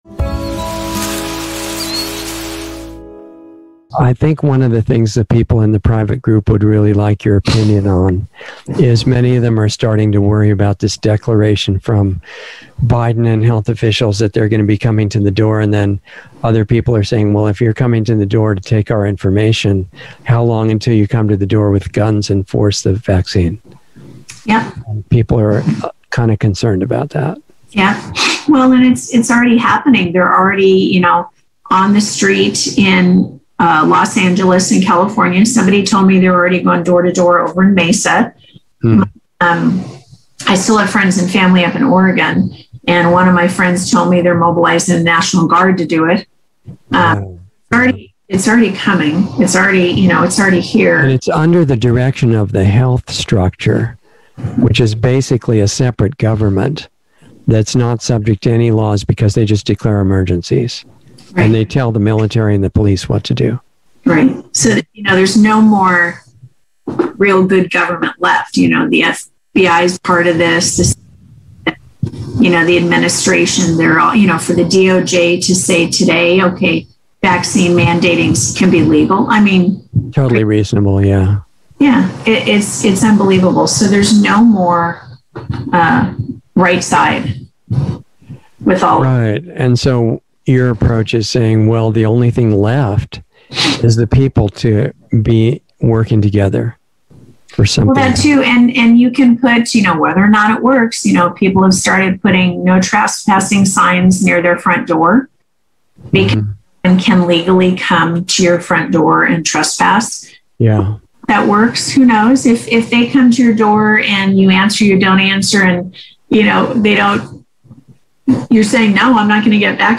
Insider Interview 7/29/21